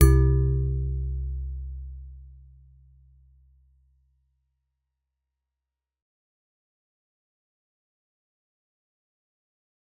G_Musicbox-C2-f.wav